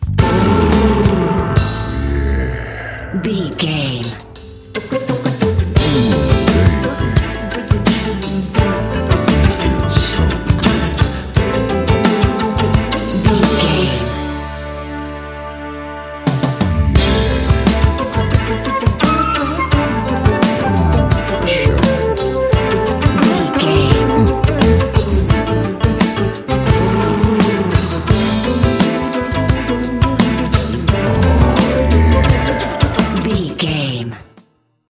Ionian/Major
funky
groovy
flute
vocals
drums
strings
Funk